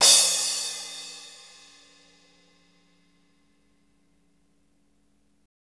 CYM CRASH05R.wav